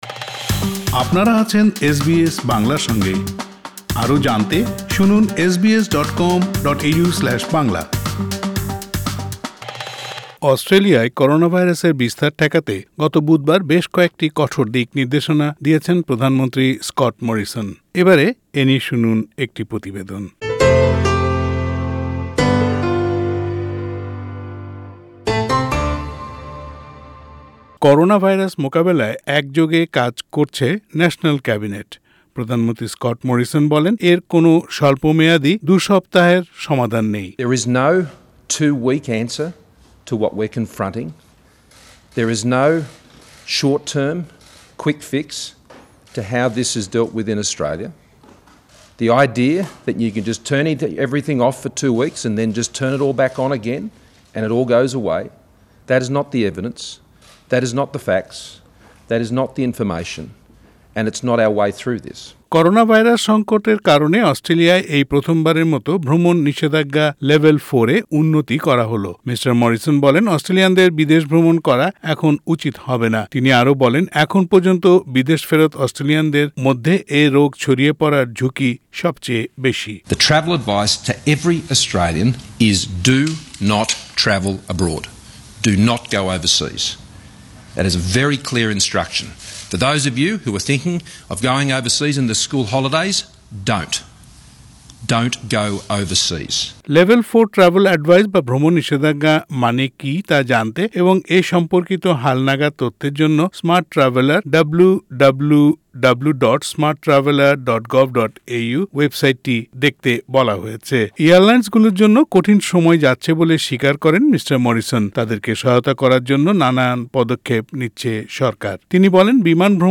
PM Scott Morrison at the media conference in Canberra Source: AAP